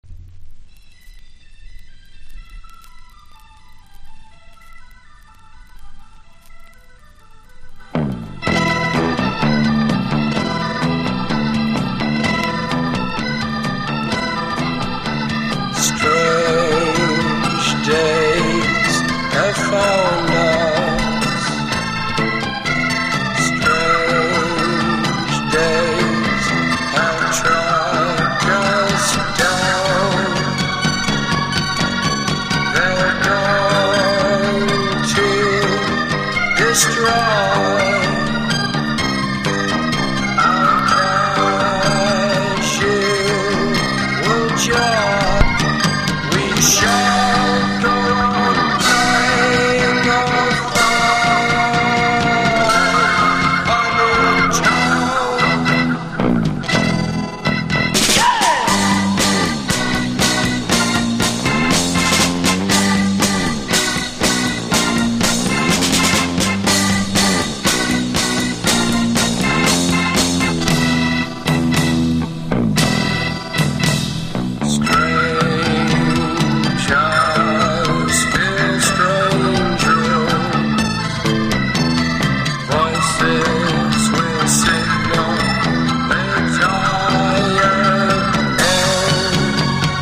1. 60'S ROCK >
PSYCHEDELIC / JAZZ / PROGRESSIVE# ロック名盤# BLUES ROCK / SWAMP